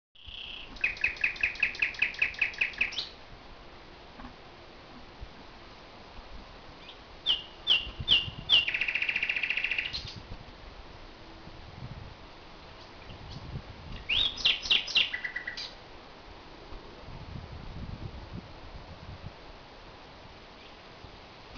Nachtigall - Süßer nächtlicher "Terror"
Im Garten hat eine Nachtigall ihr Lager aufgeschlagen und sucht nach einem Partner. Das Repertoire dieses unscheinbaren Wesen ist unglaublich. Gestern Nacht bin ich dann in der Dunkelheit um 00:30 Uhr durch die Brombeeren gerobbt und habe die Aufnahmefunktion meiner Kamera genutzt.